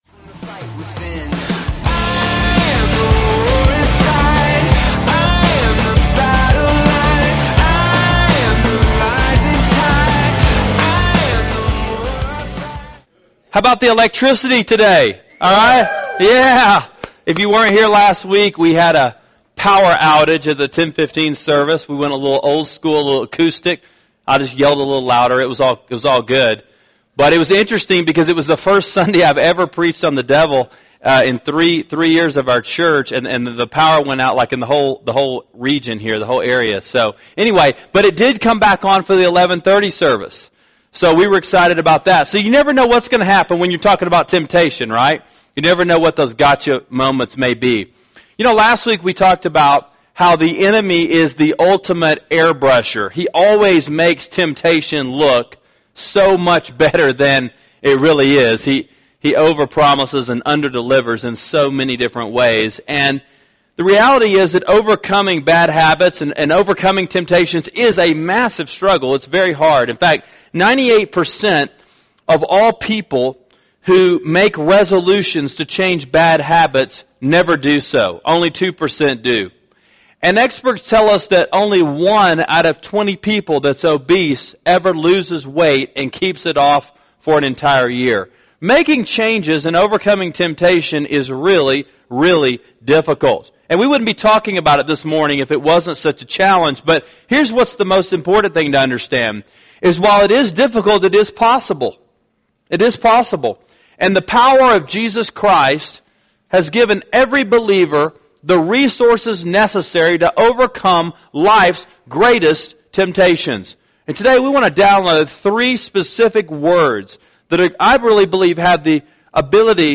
Gotcha: Take Control Of What’s Controling You – 1 Cor 10:12-13 – Sermon Sidekick